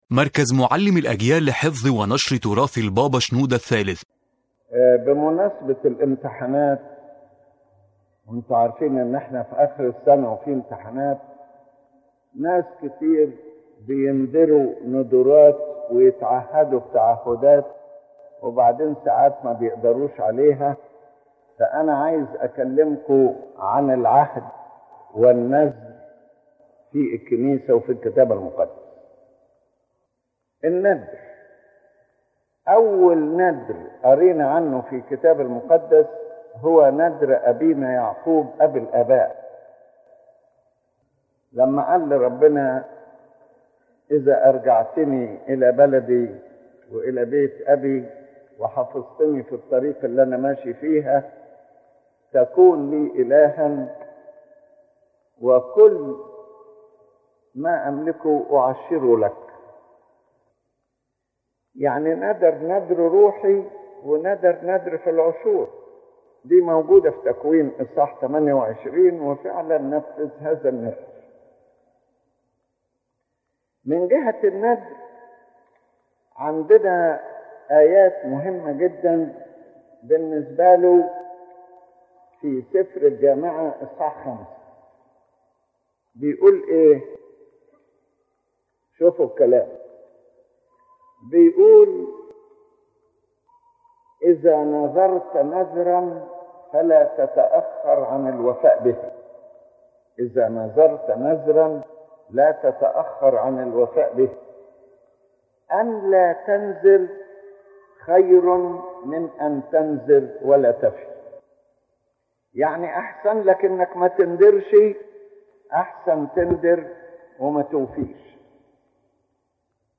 The lecture revolves around the concept of vows and covenant in the Holy Bible and the teaching of the Church, affirming that a vow is a serious spiritual commitment between the human being and God, not merely words said in times of distress or need. The vow is a sacred covenant, and whoever commits before God must be faithful in fulfilling what he vowed.